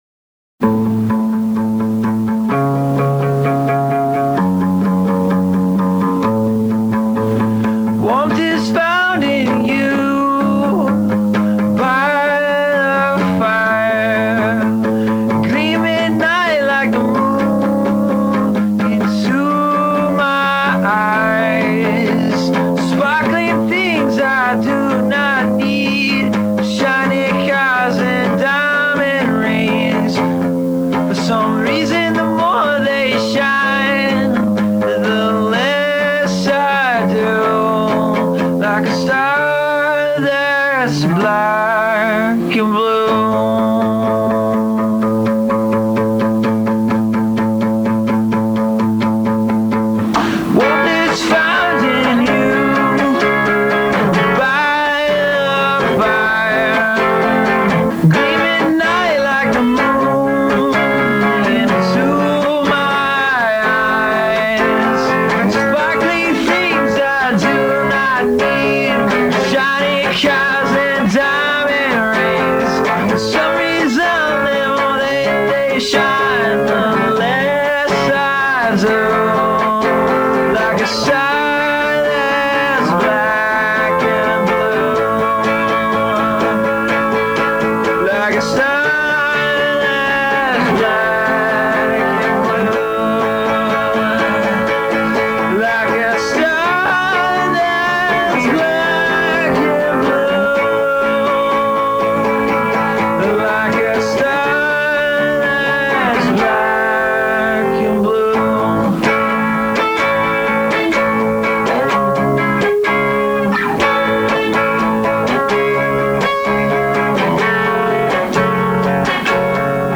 Vocals & Electric Guitar